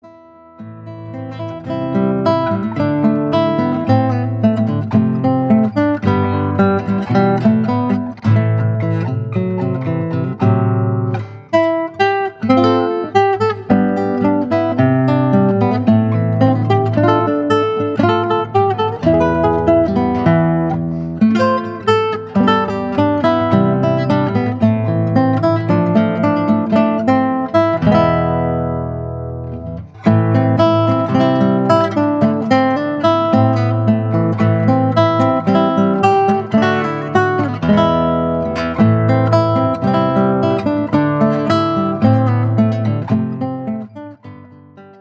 Classical guitar arrangements